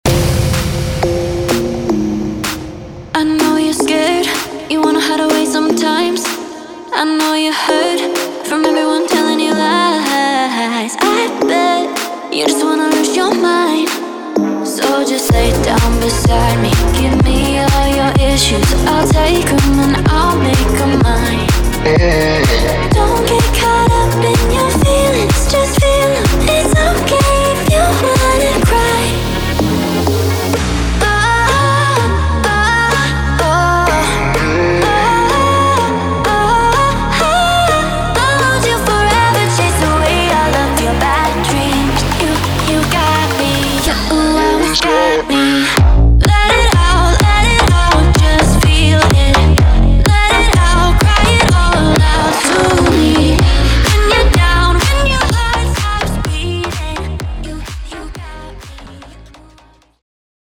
柔和迷人的人声与 Slap House 的凉爽氛围交织在一起。